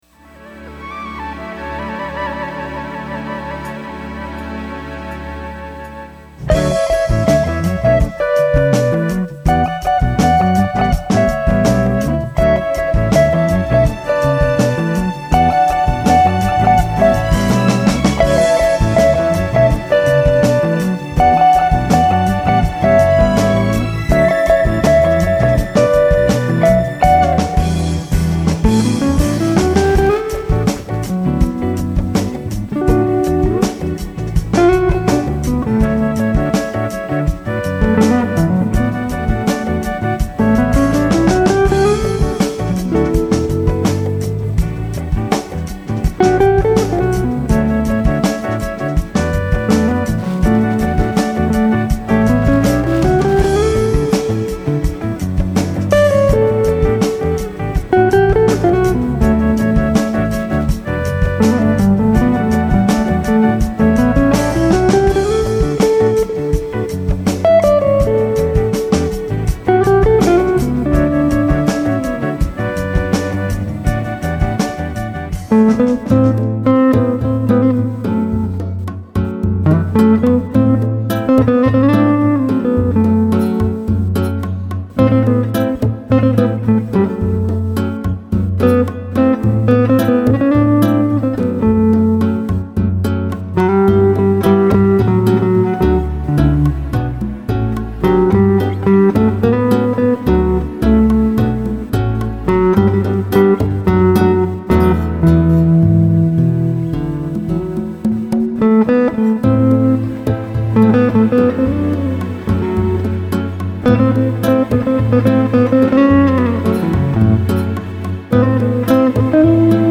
The guitar stylings